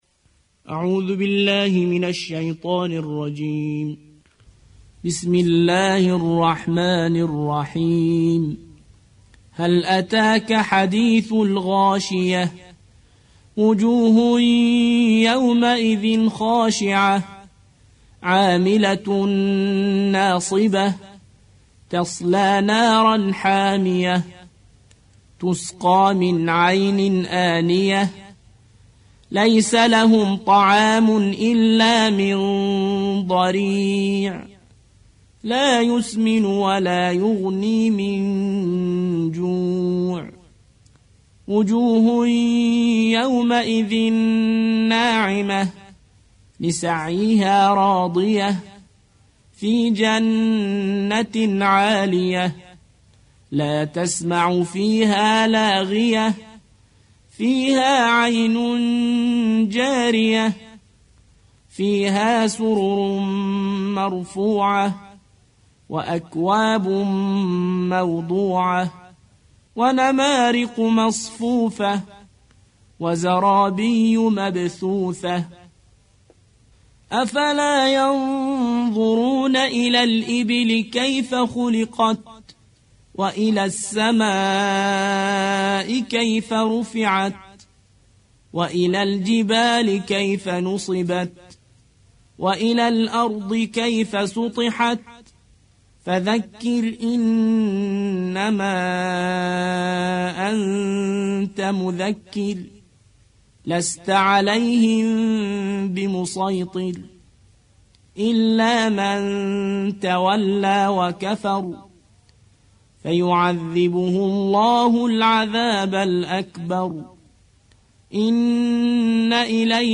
88. سورة الغاشية / القارئ